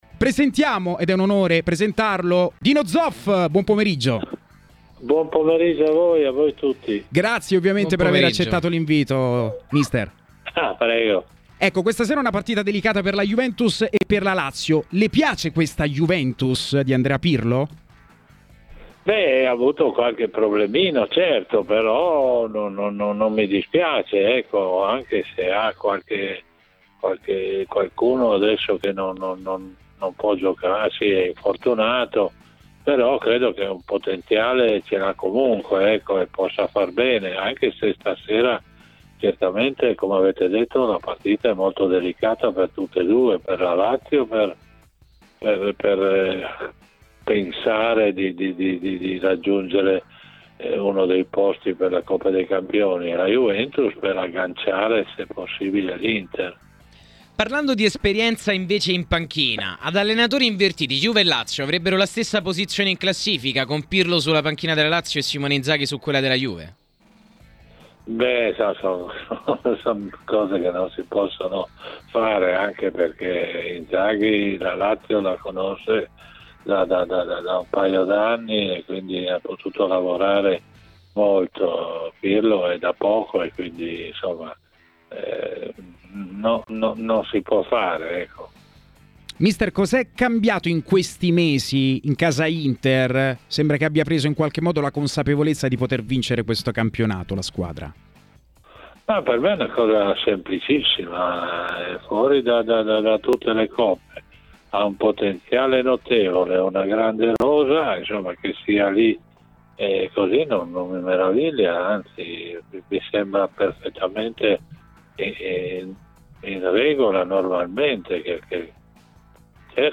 È intervenuto ai microfoni di Tmw Radio un'icona del calcio italiano, Dino Zoff.